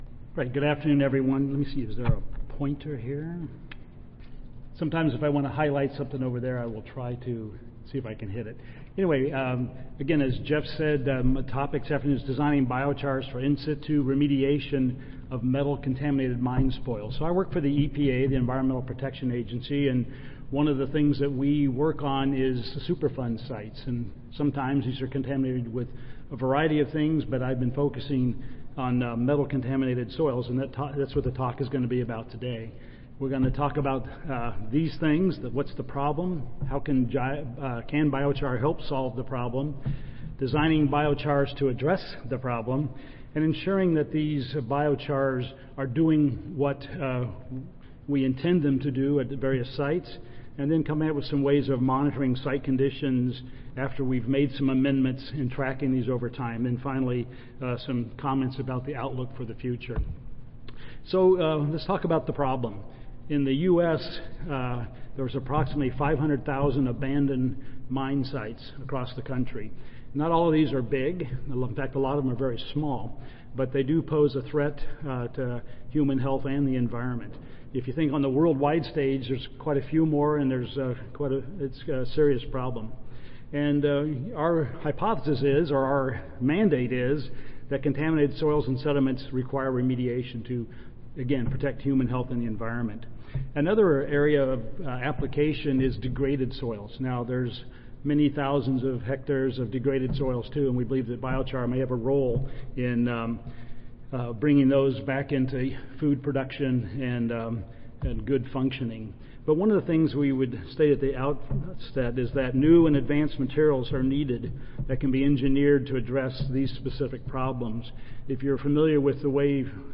USDA-ARS Audio File Recorded Presentation